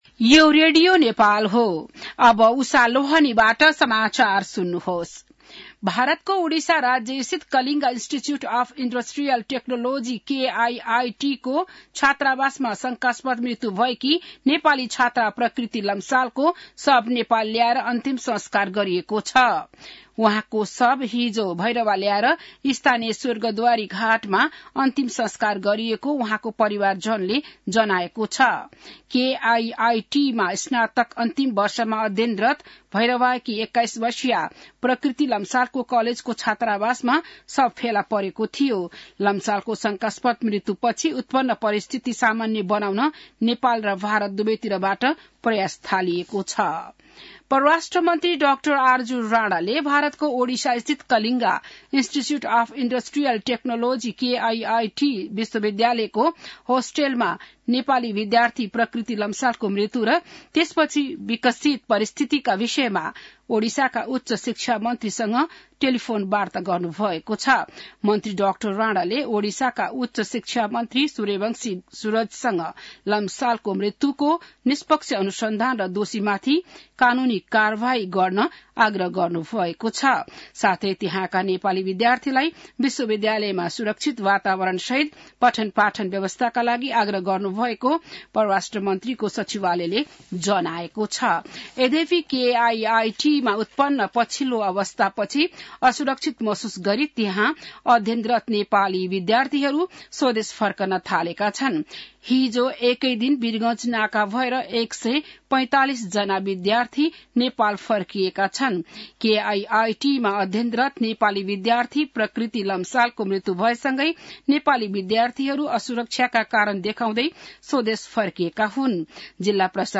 बिहान १० बजेको नेपाली समाचार : ९ फागुन , २०८१